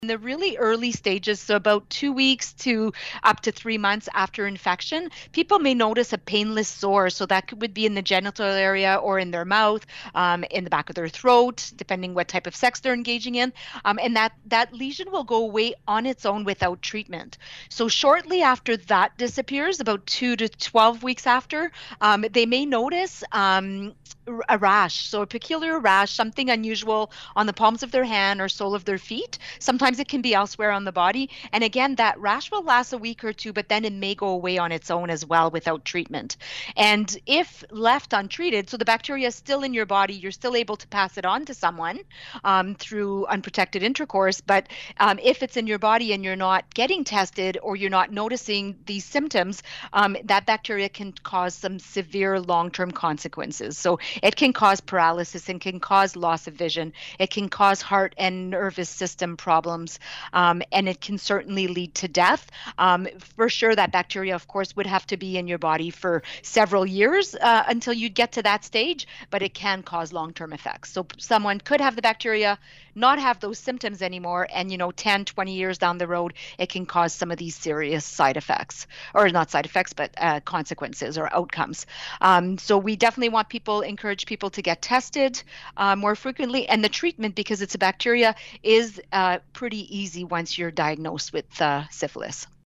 In the first audio link below is a general conversation about syphilis and chlamydia. The second one deals with what to look for regarding syphilis, and what the risks are to your health.